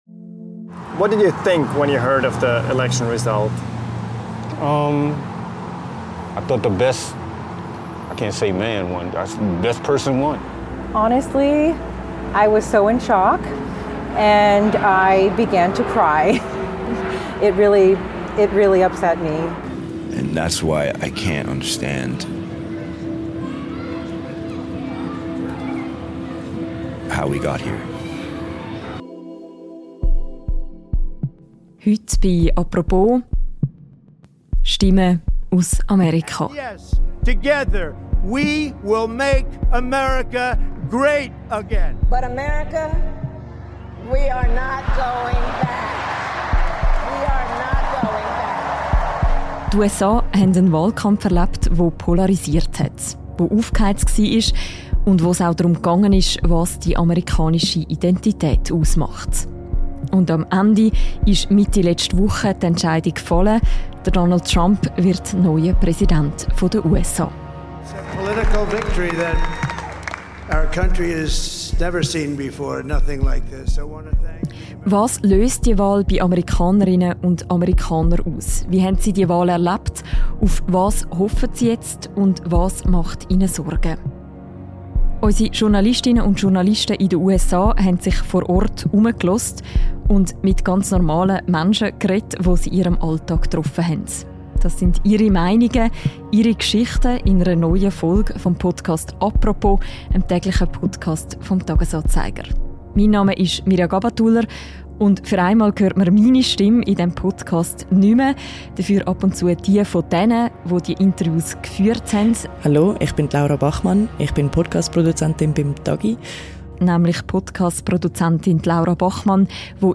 haben sich in den Tagen nach der Wahl bei alltäglichen Begegnungen auf der Strasse umgehört – in der Metropole New York, in der Stadt Phoenix in Arizona und im ländlicheren Bethlehem in Pennsylvania.